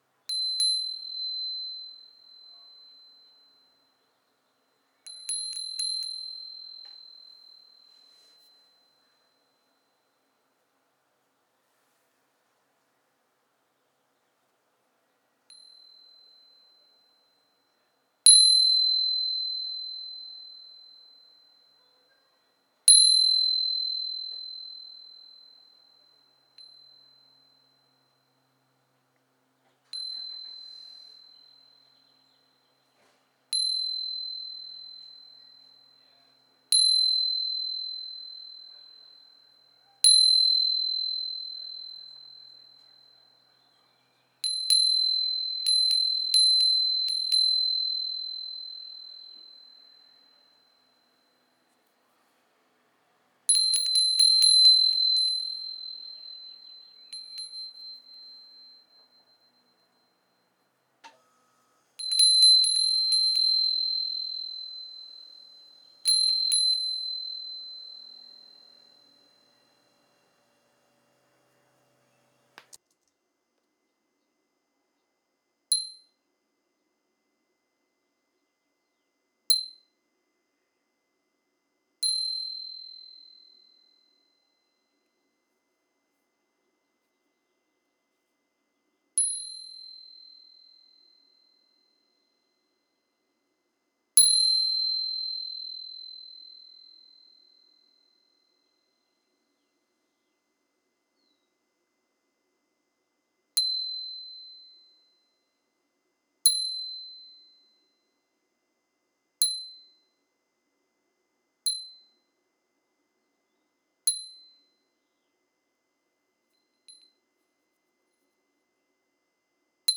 Small japanese cast iron wind chime
bell cast chime ding green iron japanese michinoku sound effect free sound royalty free Nature